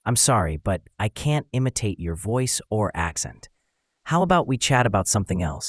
role0_VacuumCleaner_1.wav